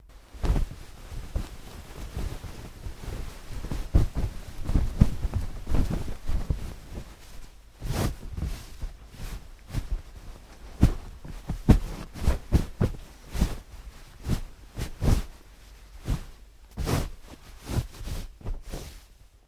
На этой странице собраны звуки, связанные с одеялом: шуршание ткани, легкое движение, уютное тепло.
Шум натягивания пододеяльника на одеяло